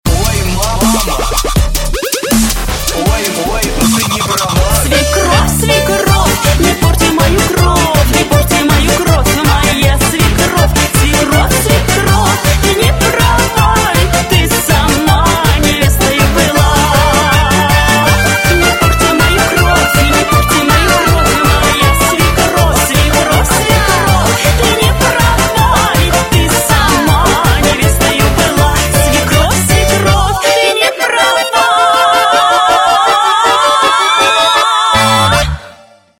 • Качество: 256, Stereo
веселые
Dubstep
смешные